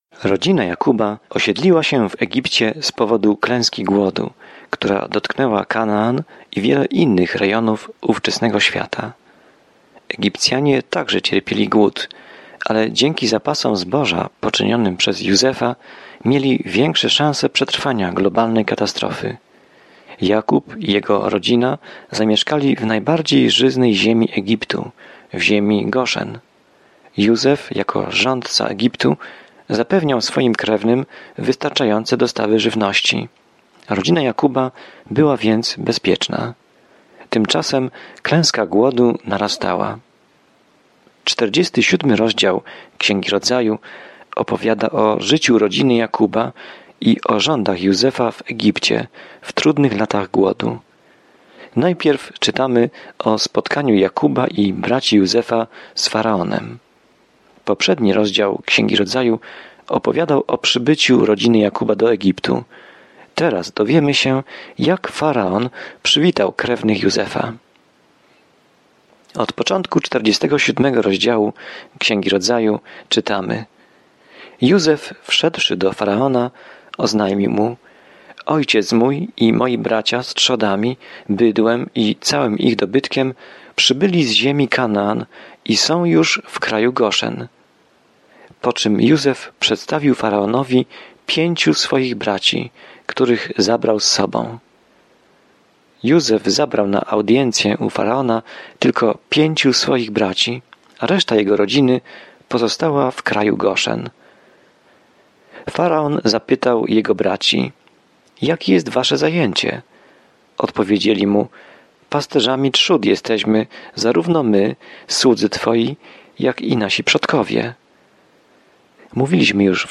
Pismo Święte Rodzaju 47 Dzień 53 Rozpocznij ten plan Dzień 55 O tym planie Tutaj wszystko się zaczyna – wszechświat, słońce i księżyc, ludzie, relacje, grzech – wszystko. Codziennie podróżuj przez Księgę Rodzaju, słuchając studium audio i czytając wybrane wersety słowa Bożego.